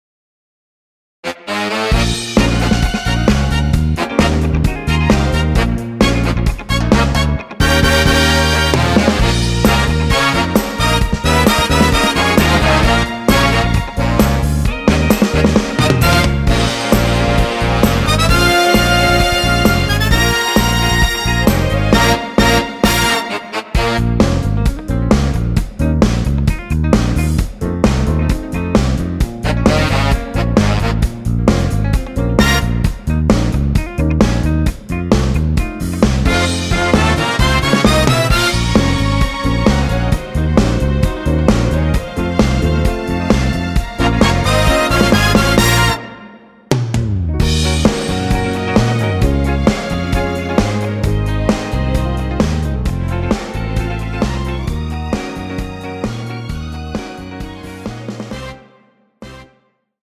MR입니다.
원키에서(+1)올린 MR입니다.
Bbm
앞부분30초, 뒷부분30초씩 편집해서 올려 드리고 있습니다.